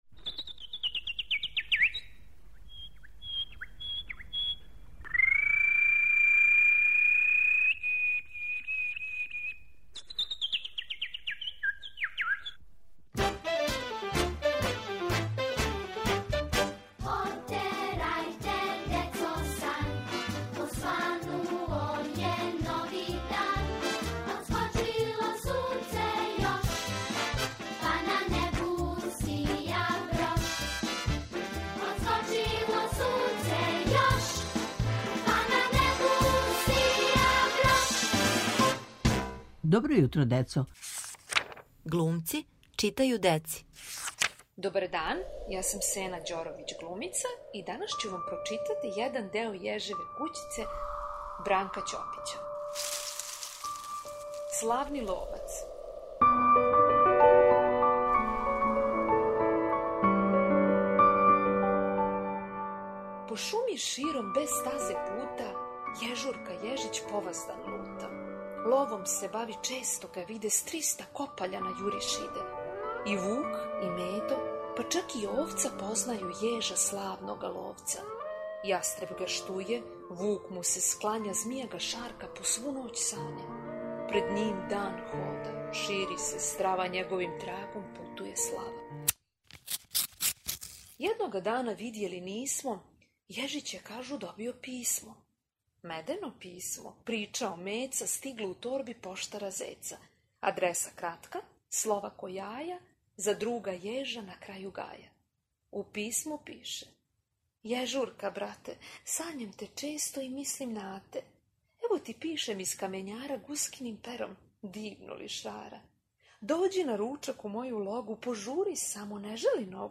У нашем серијалу "Глумци читају деци", глумица Сена Ђоровић читам вам одломак из "Јежеве кућице" Бранка Ћопића.